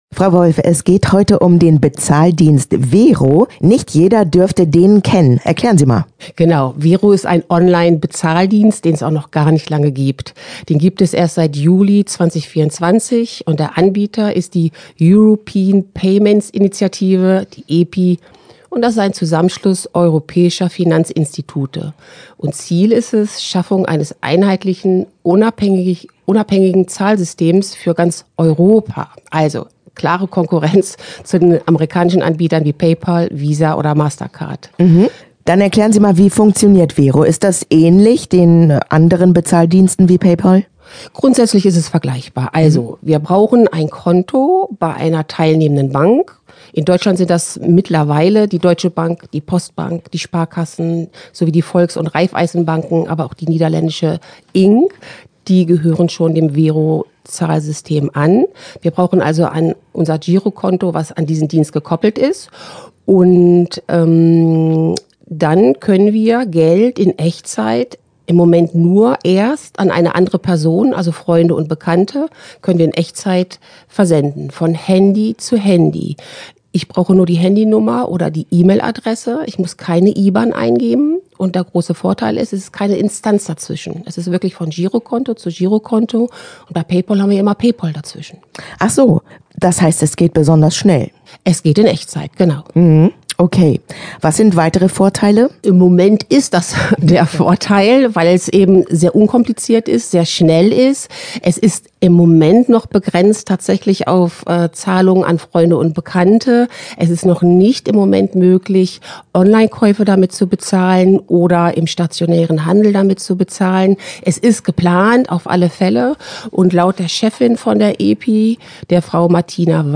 Verbraucherschutz im Gespräch: Konkurrenz für PayPal & Co? Der Online-Bezahldienst „Wero“ - Okerwelle 104.6